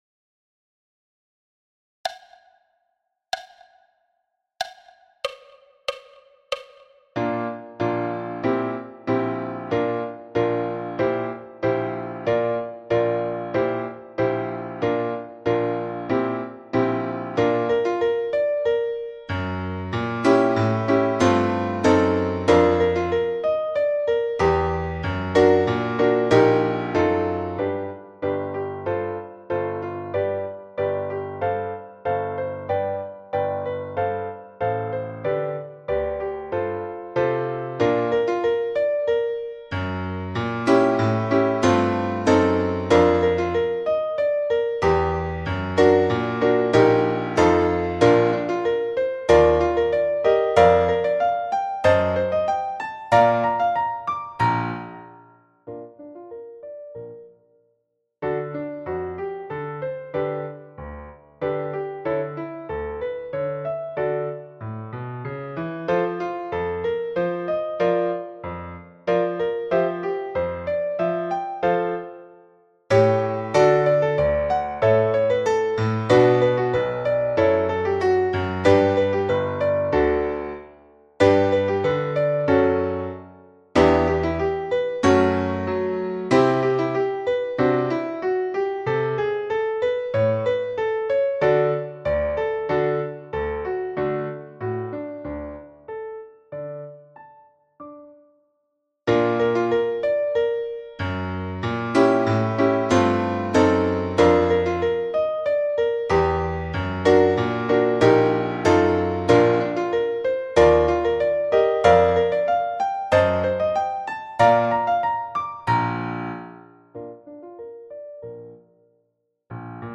Parameters à 94 bpm